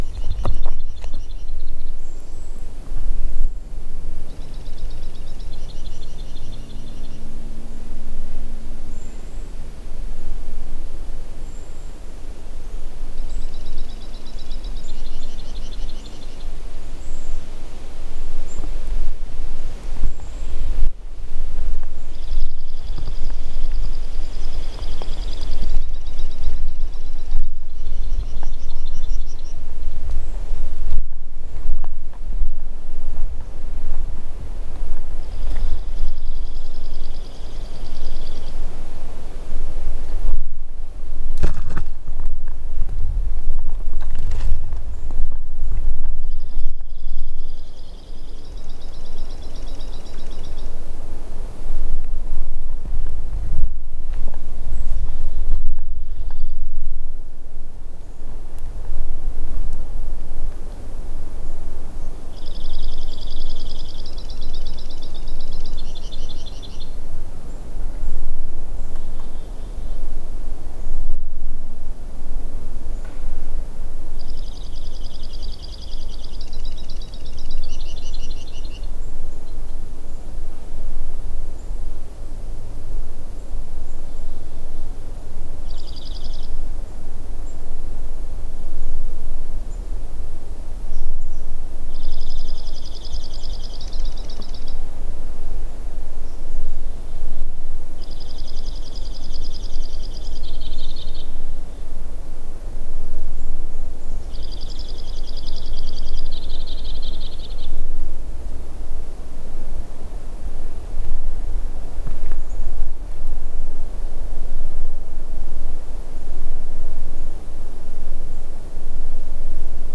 White-winged Crossbill
Summerhill (Salt Rd. near Dresser Rd.), 13 August 2008
Complete recording (big) of singing male